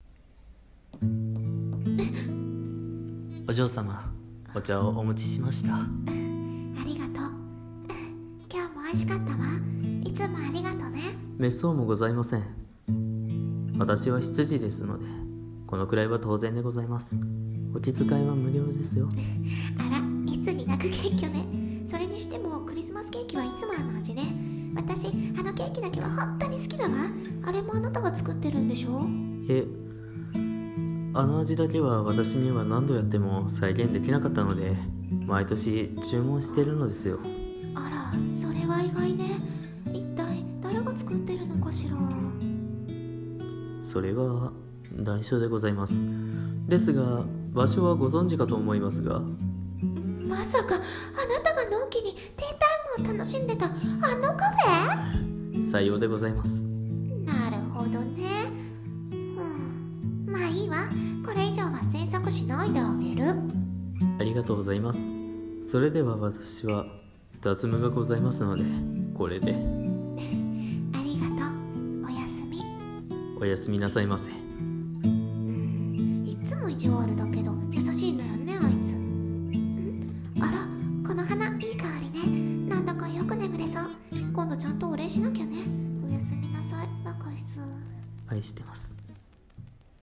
【声劇】お嬢様と『ディナーの後に』